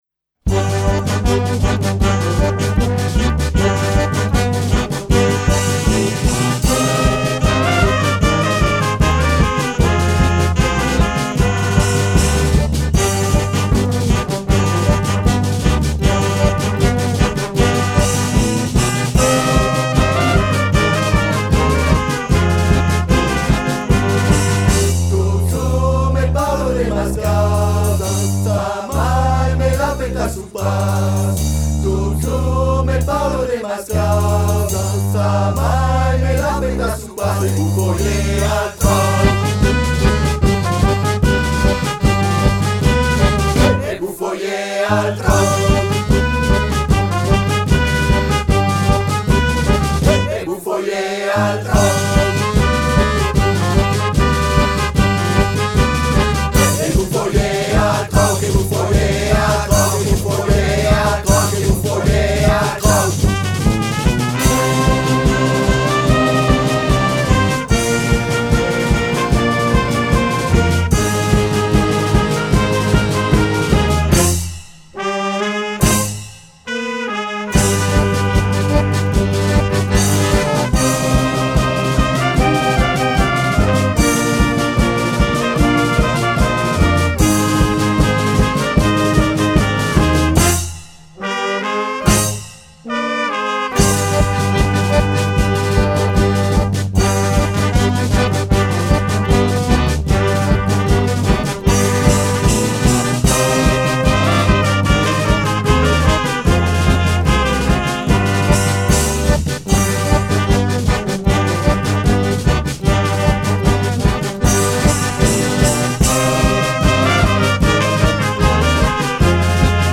Enregistré et mixé au studio Millau’Zic-Priam